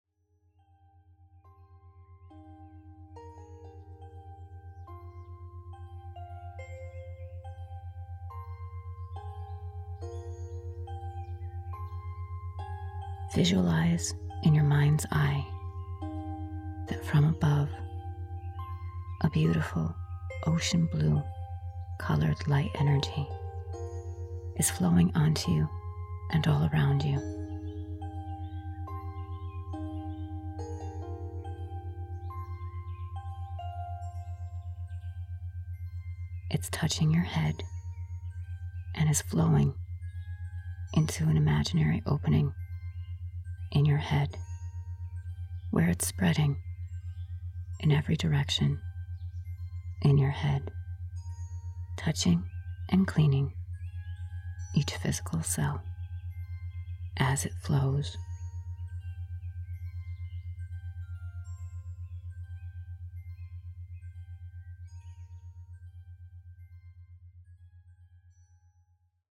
Chakra Healing with Colors Meditation
SAMPLE-of-Chakra-Healing-with-Colors-Meditation.mp3